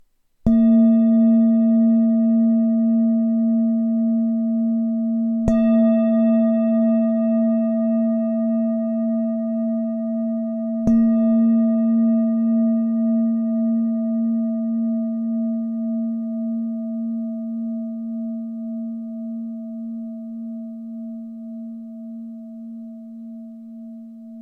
Matná tibetská mísa A#3 17cm
Nahrávka mísy úderovou paličkou:
Jde o ručně tepanou tibetskou zpívající mísu dovezenou z Nepálu.